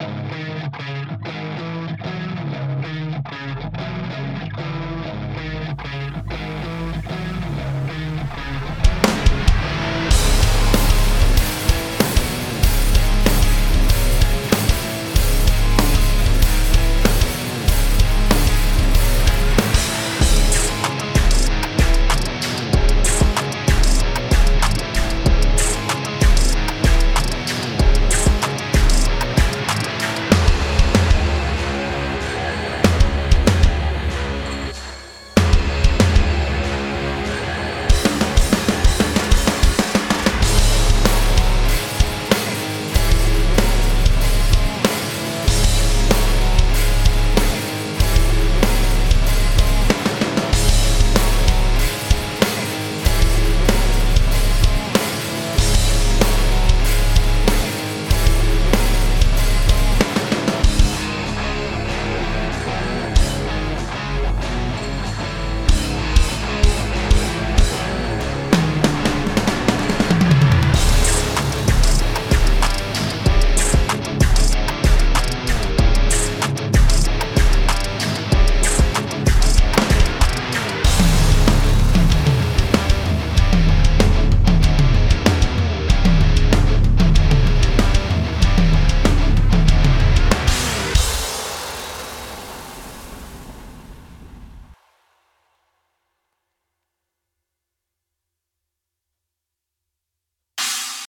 Crank the distortion with Trap Metal insanity